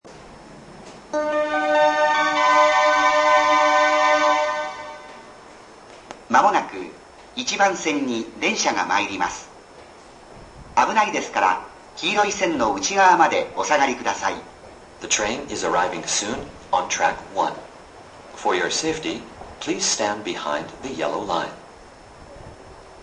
さて、愛知環状鉄道は一部の駅で接近放送と接近メロディを導入しています。
アナウンスは英語付きで、岡崎方面は男声、高蔵寺方面は女声となっています（ただし岡崎駅は全て男声、高蔵寺駅と八草駅は全て女声）。
１番ホーム接近放送